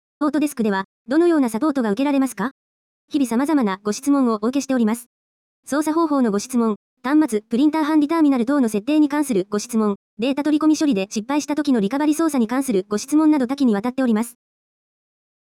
そのような方に、まずは耳からお試しいただけるよう、【ci.Himalayas/WMS よくあるご質問】を実際のシステム音声でサウンド化しました。
それでは、CCSの音声システムで実際に稼動しているシステム音声による【ci.Himalayas/WMS よくあるご質問】をお試しください。